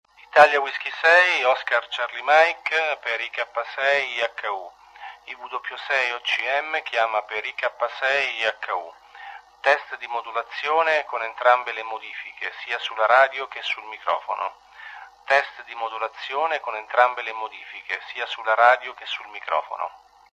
Con entrambe le modifiche la modulazione diventa molto più fedele e gradevole a chi ascolta.